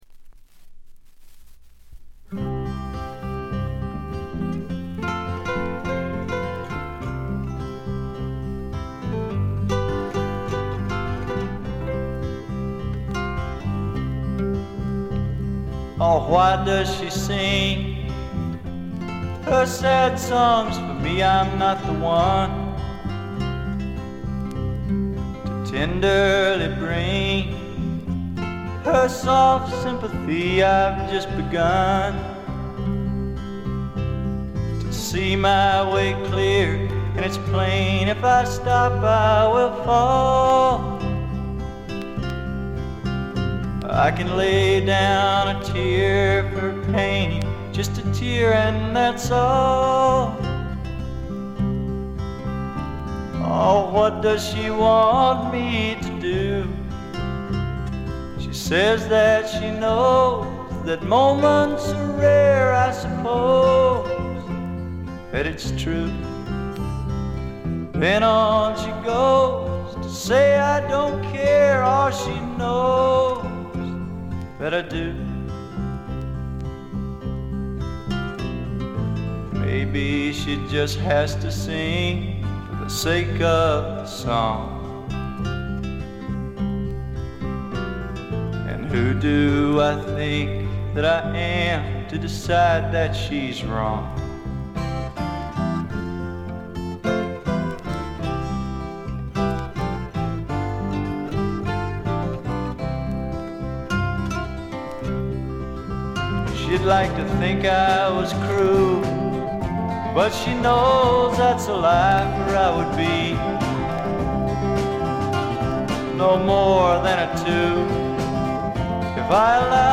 極めて良好に鑑賞できます。
試聴曲は現品からの取り込み音源です。